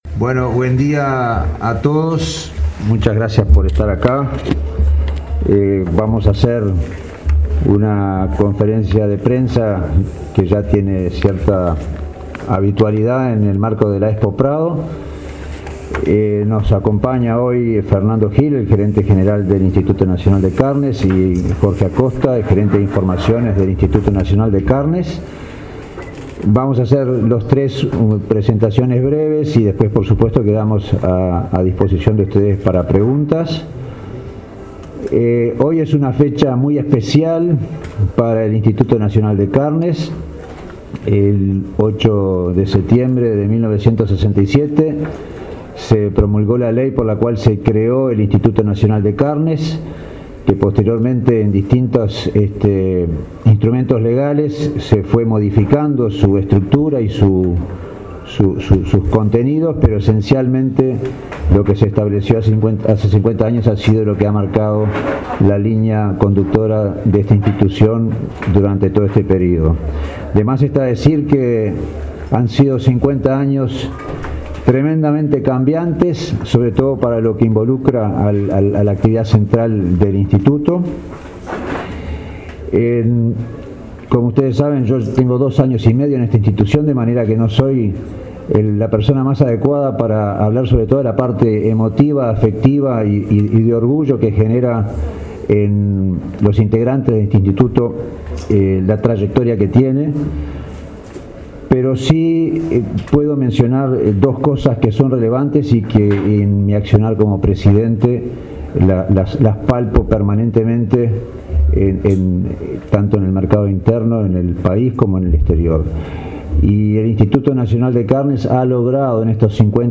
conferencia.mp3